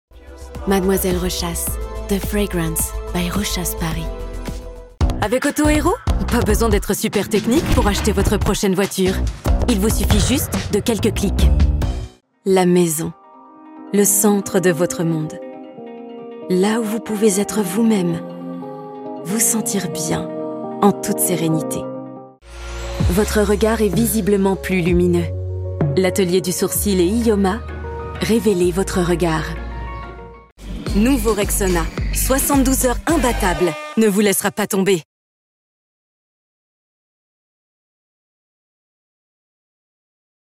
Natural, Distinctive, Soft, Commercial, Friendly
Commercial